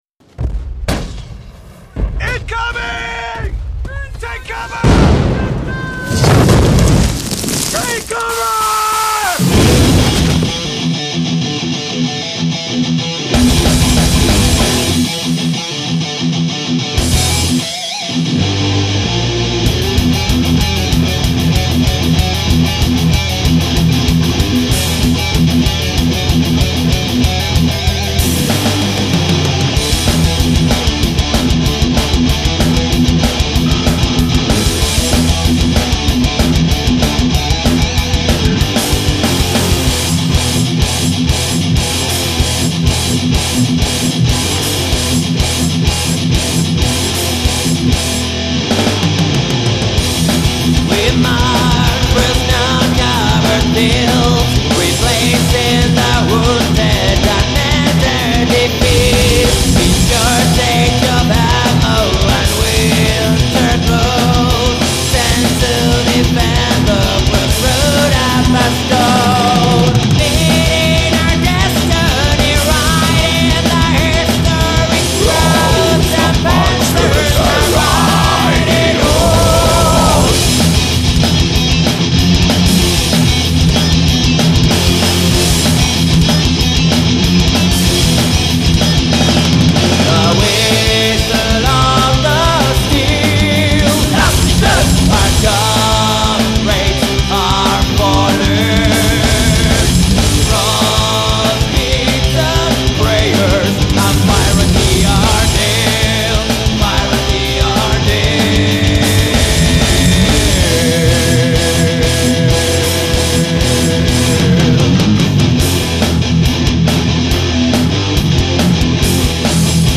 temas melódicos con guitarras potentes.